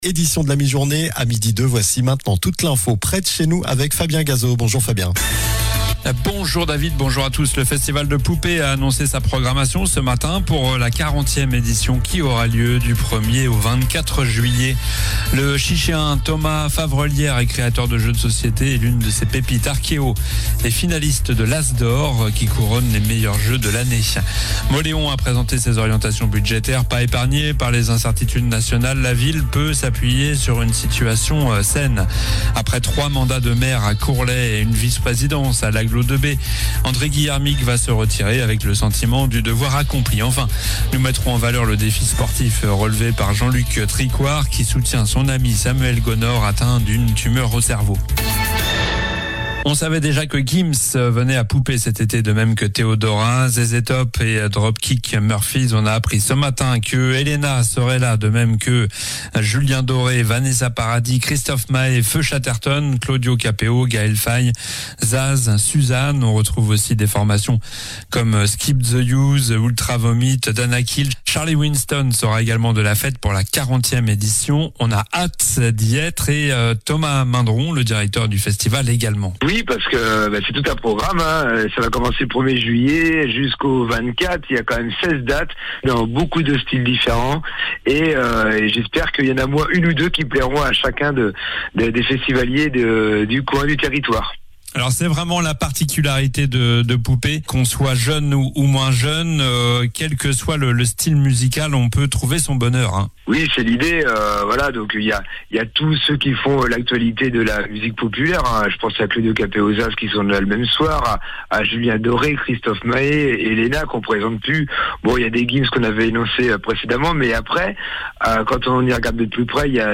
Journal du jeudi 29 janvier (midi)